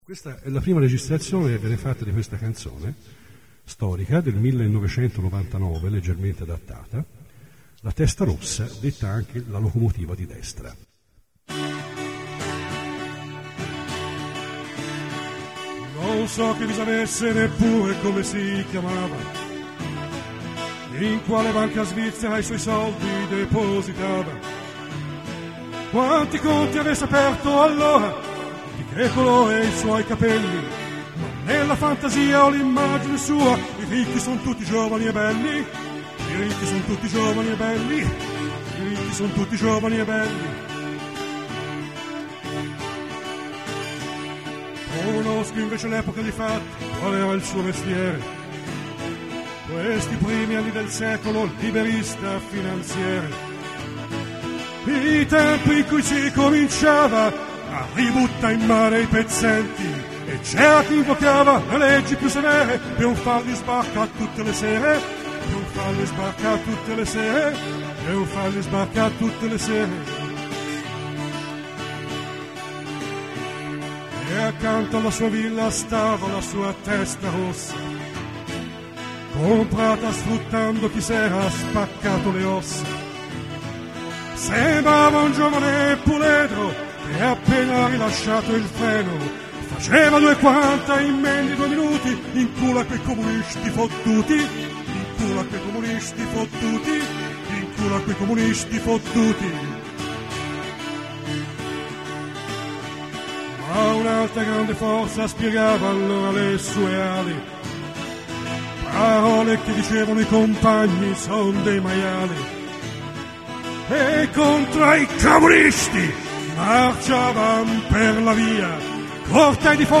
io ho dato il mio contributo unicamente chitarristico